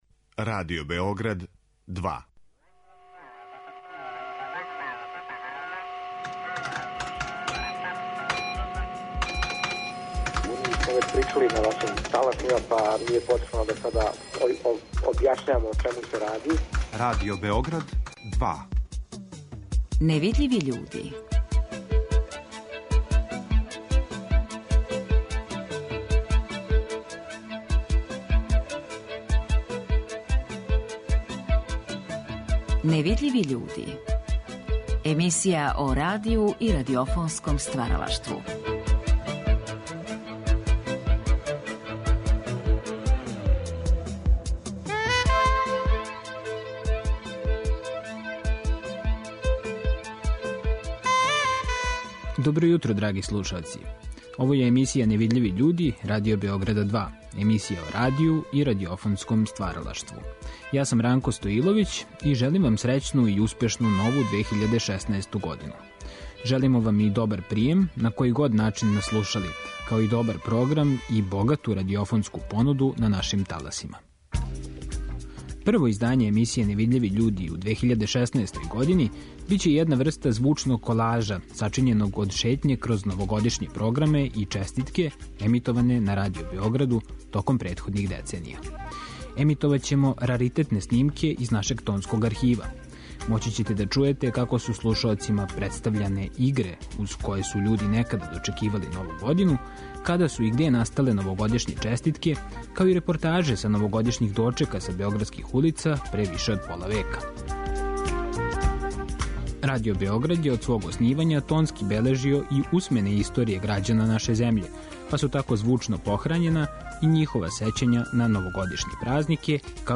Прво издање емисије 'Невидљиви људи' у 2016. години биће једна врста звучног колажа сачињеног од шетње кроз Новогодишње програме и честитке емитоване на Радио Београду претходних деценија.
Чућете како су 1. јануара далеке 1958. године у емисији "Радио вам одговара" слушаоцима представљене игре уз које су људи некада дочекивали Нову годину, као и када су настале новогодишње честитке. Из новогодишњег "Дневника" последњег дана децембра 1960. издвојили смо репортажу са београдских улица непосредно уочи дочека.
Пригодне радио-драме, хумореске, скечеви и вињете су бојили Новогодишњи програм на таласима Радио Београда, па ћете чути и избор из ових празничних емисија.